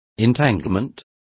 Also find out how maranas is pronounced correctly.